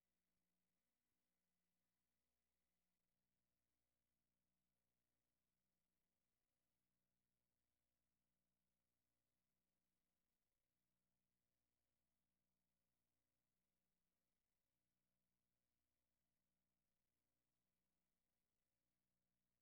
WORLD SOUNDSCAPE PROJECT TAPE LIBRARY
1. Queen Elizabeth Park, from lookout in front of Bloedel Conservatory 5:12
1. ID, some wind noise on mic, quiet ambience